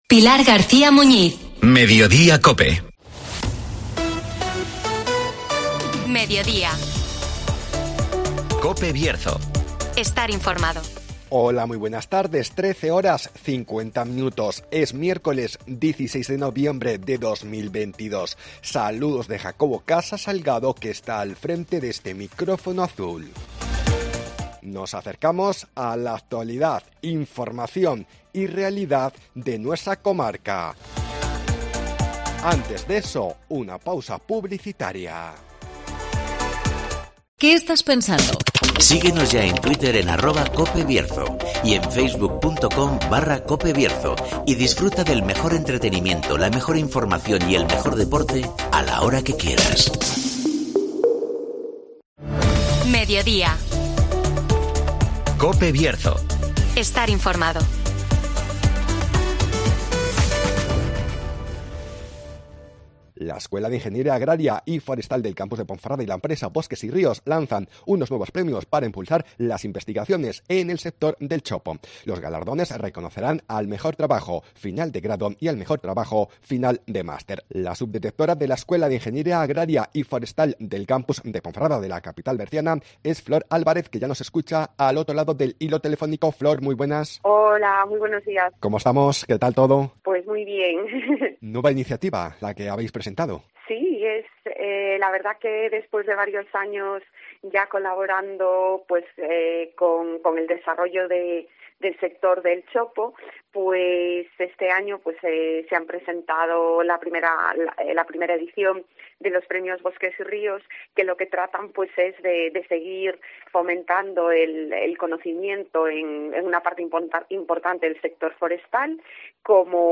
Nos acercamos a la Escuela Agraria y Forestal de Ponferrada (Entrevista